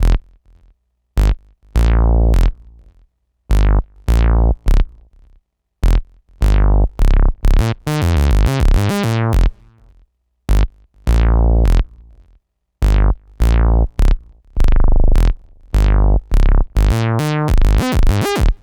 Bass 37.wav